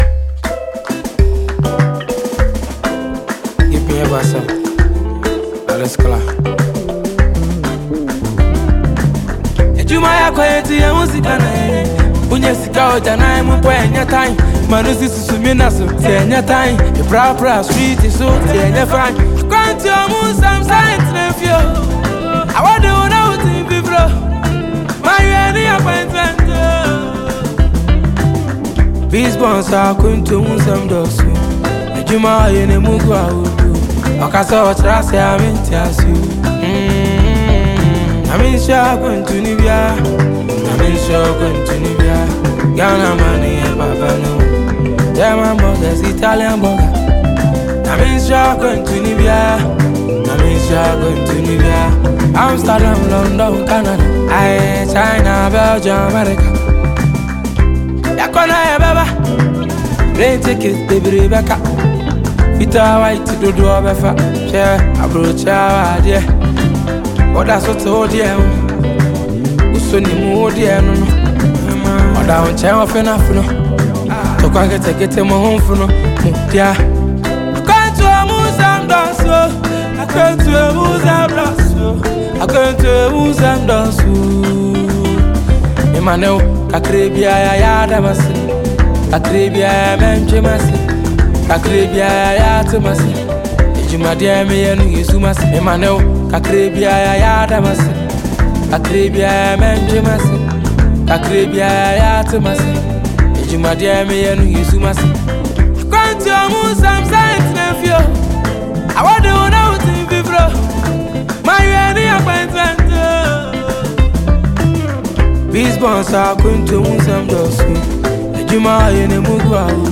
a Ghanaian vocalist and highlife singer